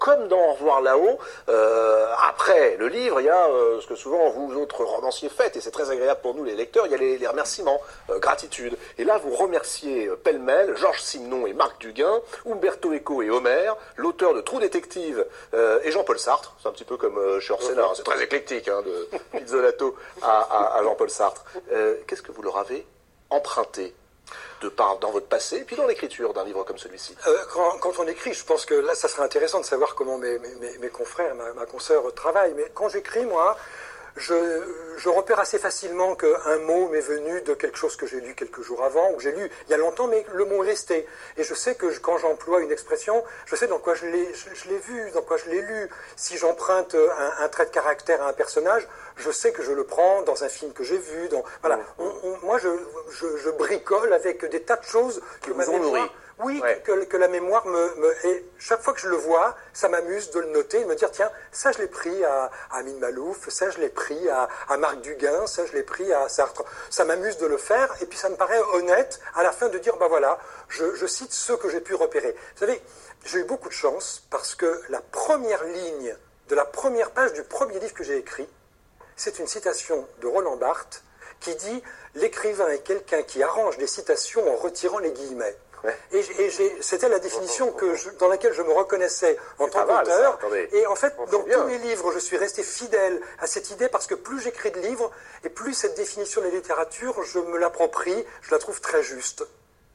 Voici comment en parlait Pierre Lemaître à la télévision il y a quelque temps.